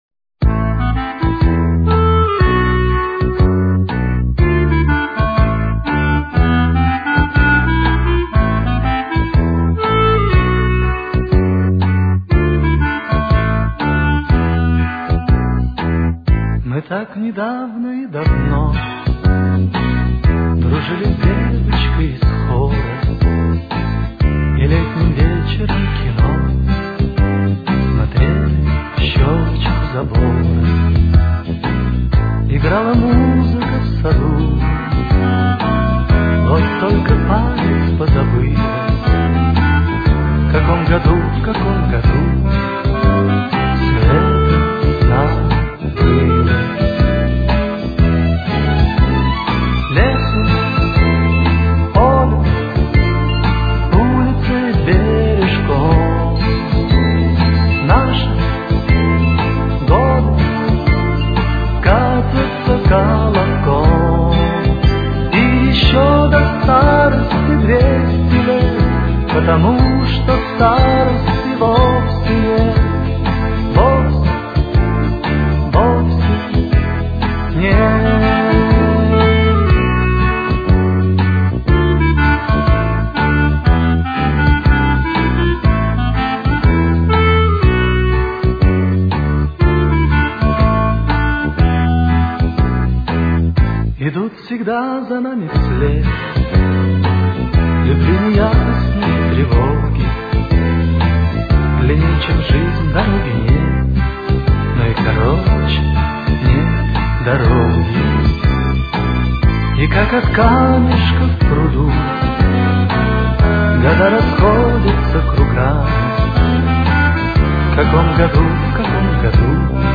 Темп: 124.